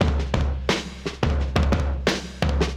• 87 Bpm High Quality Drum Groove C# Key.wav
Free drum loop sample - kick tuned to the C# note. Loudest frequency: 1004Hz
87-bpm-high-quality-drum-groove-c-sharp-key-Qio.wav